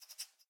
sounds / mob / rabbit / idle2.ogg